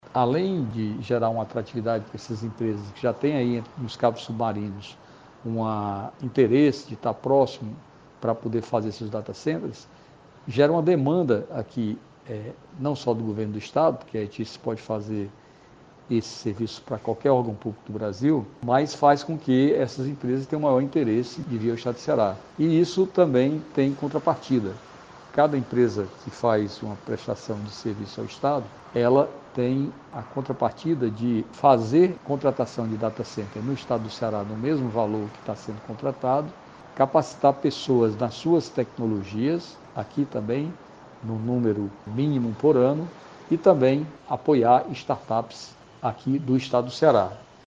O secretário Júlio Cavalcante fala sobre as oportunidades de negócios e geração de investimentos que esses cabos submarinos.